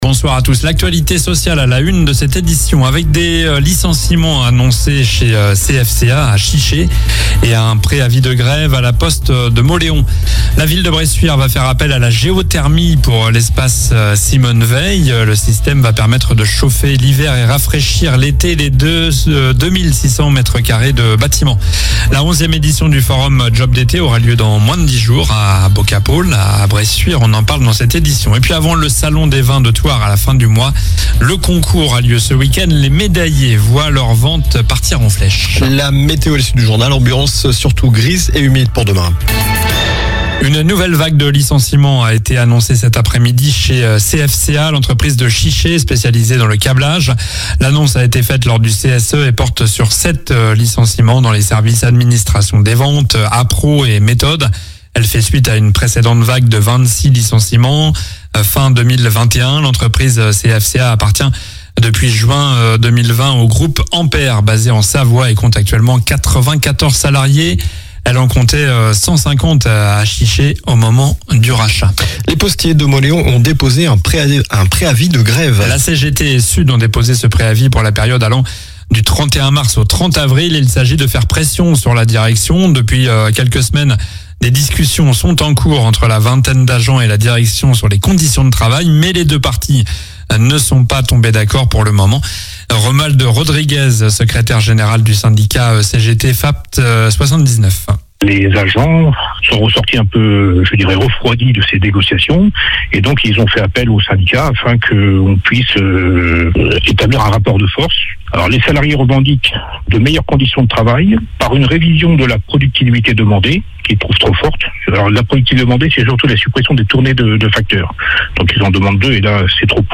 Journal du jeudi 20 mars (soir)